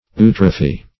Search Result for " eutrophy" : The Collaborative International Dictionary of English v.0.48: Eutrophy \Eu"tro*phy\, n. [Gr.